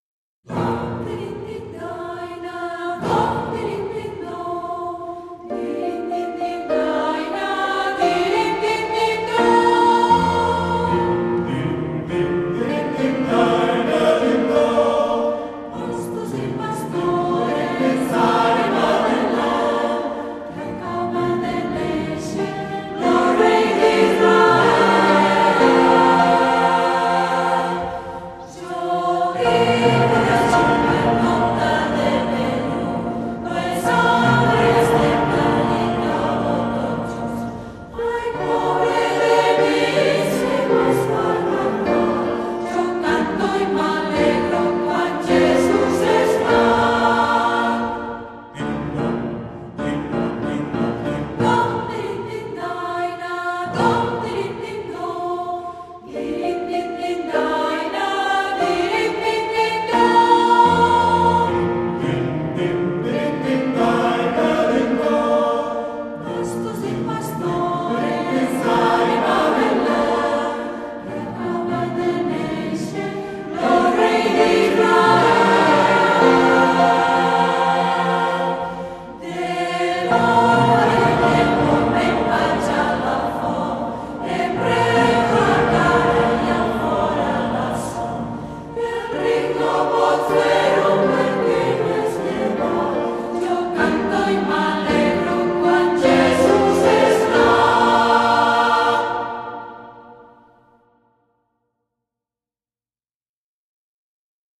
Si b M
Sib 2 - Re 4